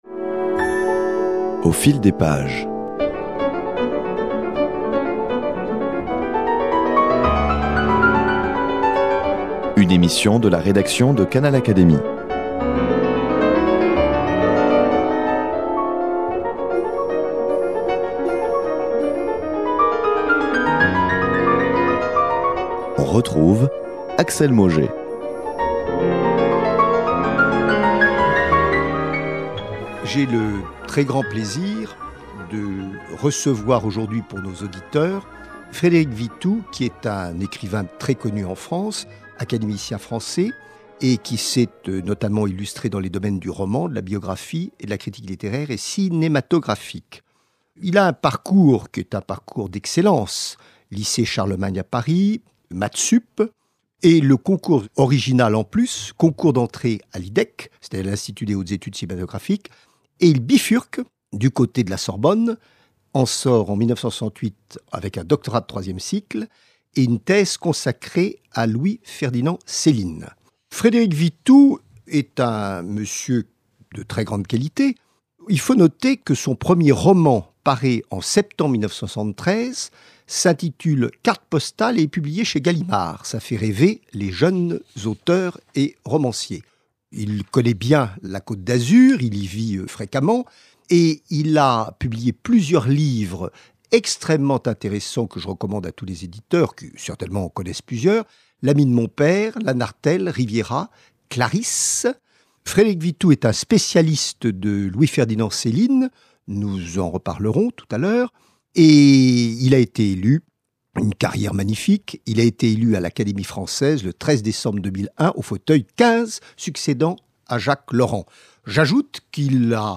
entretien exclusif